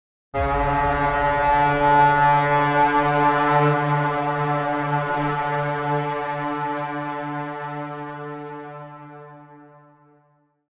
جلوه های صوتی
دانلود صدای شیپور جنگ 4 از ساعد نیوز با لینک مستقیم و کیفیت بالا